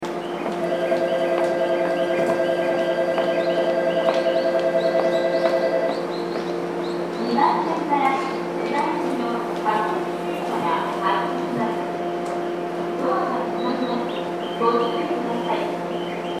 本千葉駅　Hon-Chiba Station ◆スピーカー：小丸VOSS,CLD標準型
2番線発車ベル
hon-chiba2ban.mp3